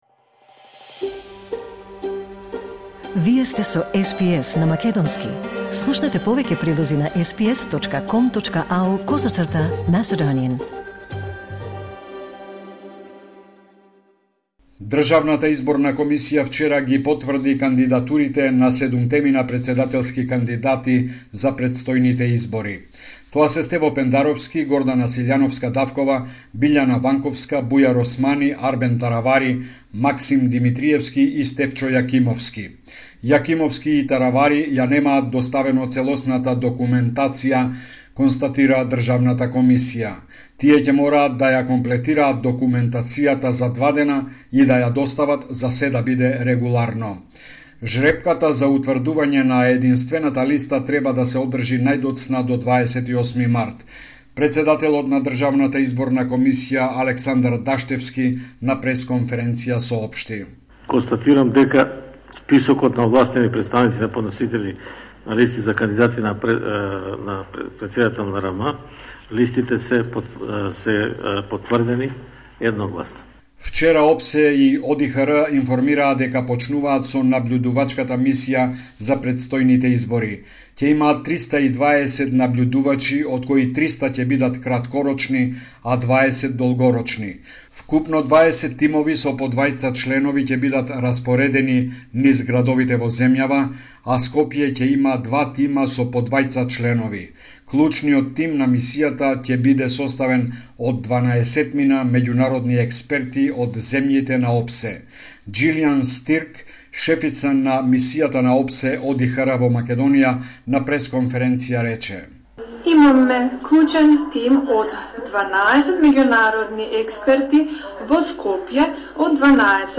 Homeland Report in Macedonian 22 March 2024